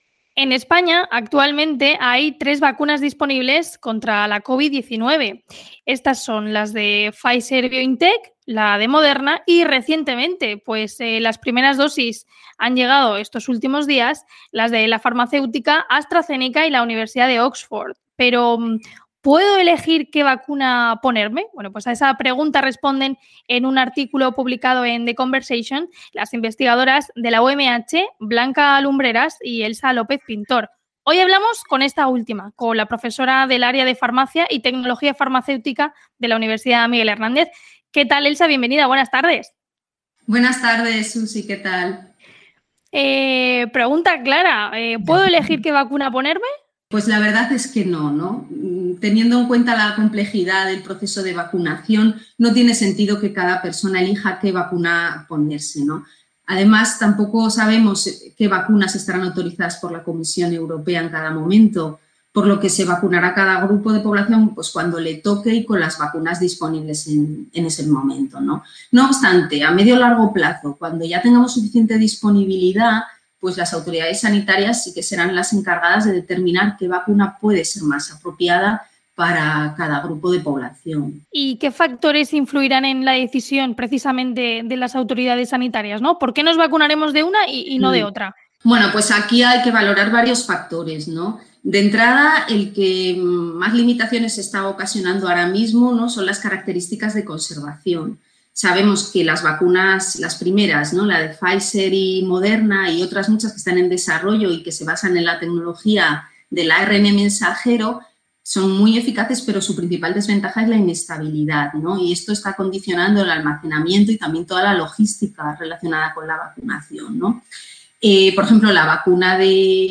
Este programa de noticias se emite de lunes a viernes, de 14.00 a 14.10 h